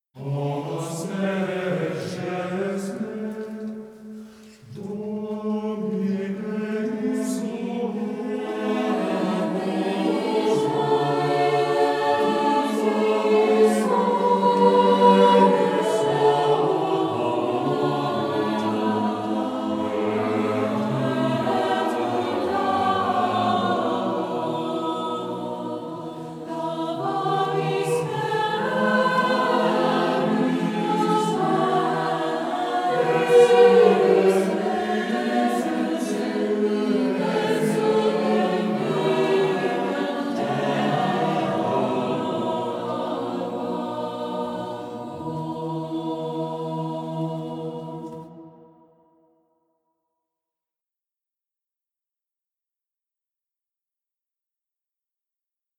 organ, I conducted